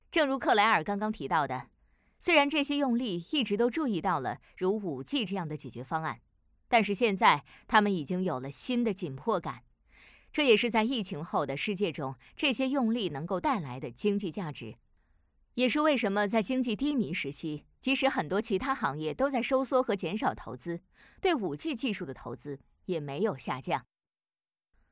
Chinese_Female_047VoiceArtist_2Hours_High_Quality_Voice_Dataset
Text-to-Speech